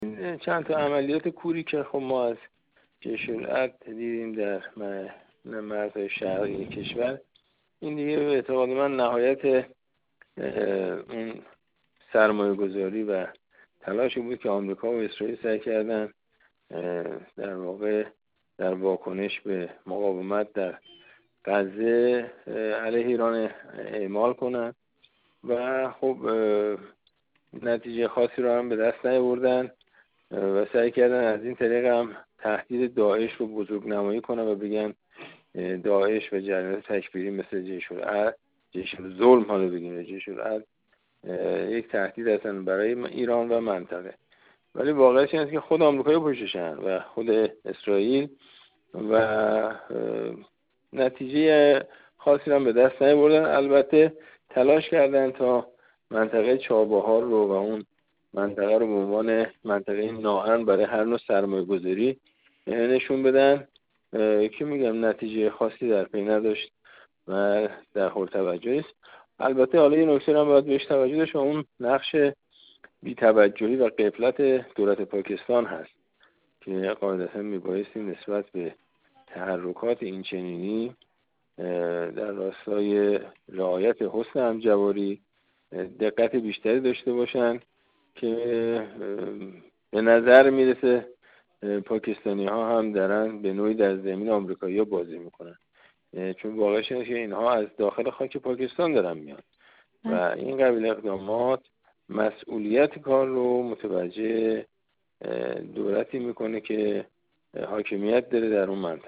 ابوالفضل ظهره‌وند، سفیر اسبق ایران در افغانستان و ایتالیا